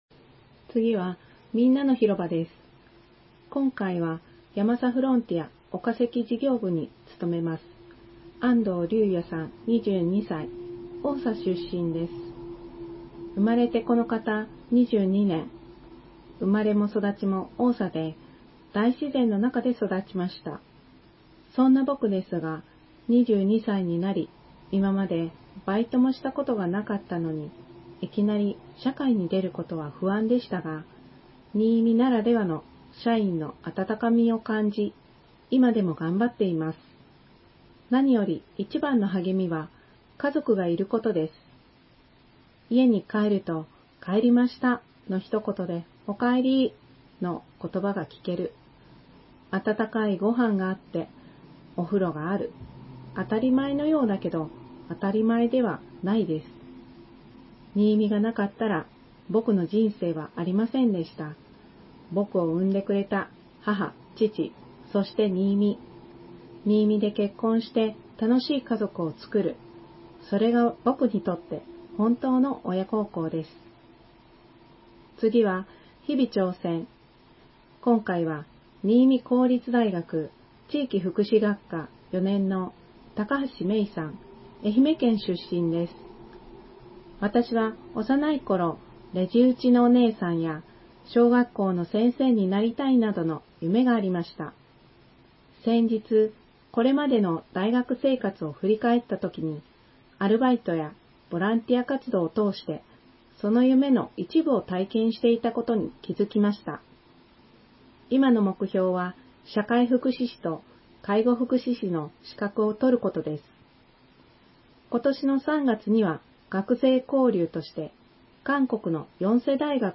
声の市報にいみの会から、声の市報８月号を提供いただきました。
市報にいみ８月号の概要を音声でお伝えします。